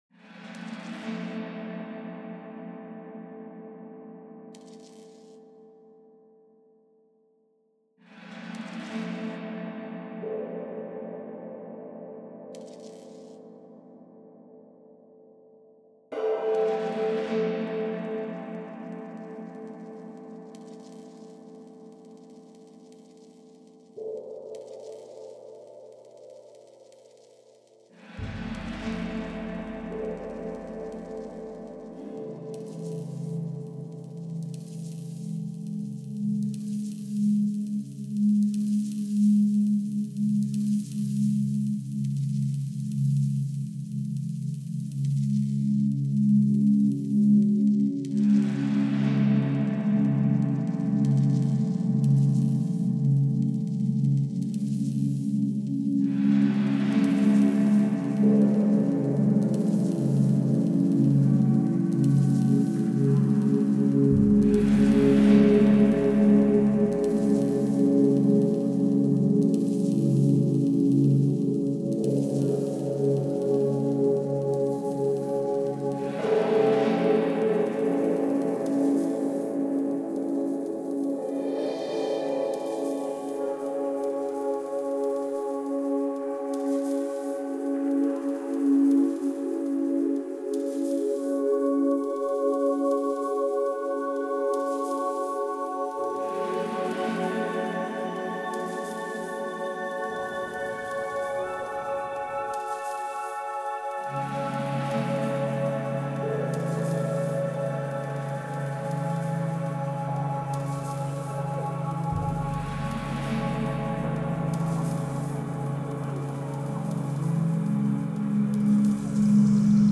Lulworth Cove reimagined